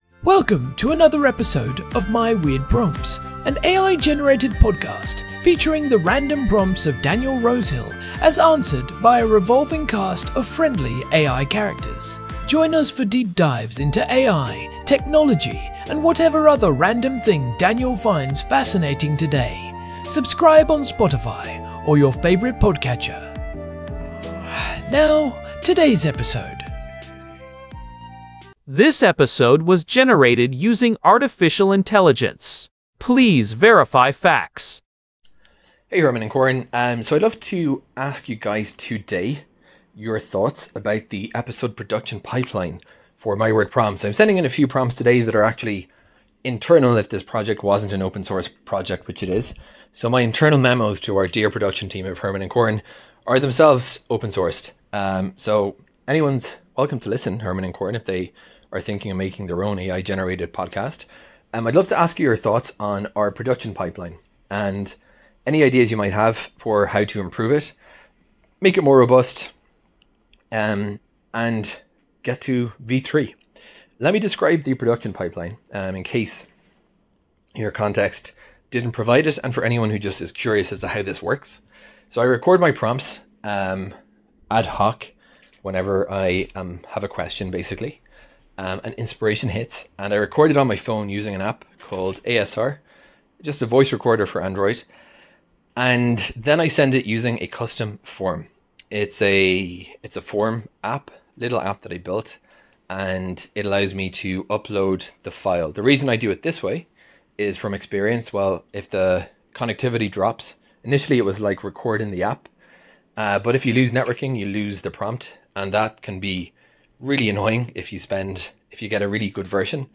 The pipeline involves several agents for planning, scriptwriting, and review, with text-to-speech using custom voice clones.